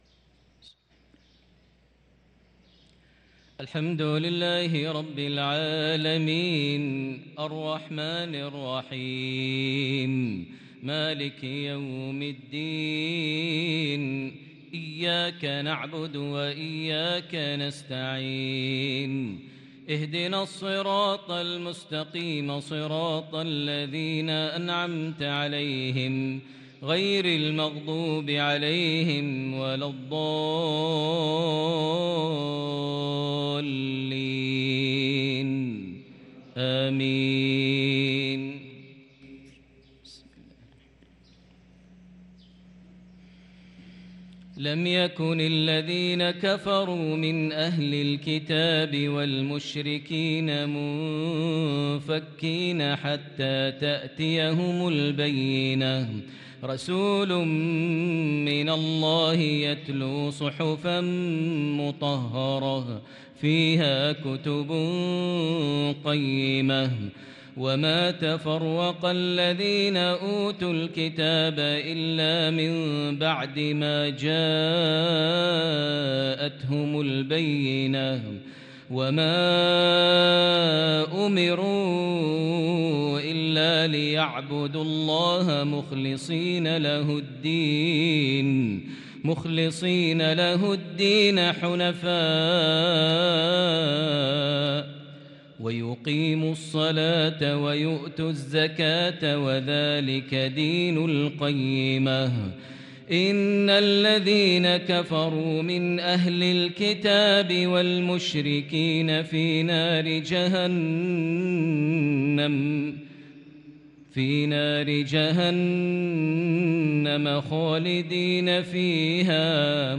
Maghreb prayer from suratee al-Bayyinah & al-`Adiyat 7-9-2022 > 1444 H > Prayers - Maher Almuaiqly Recitations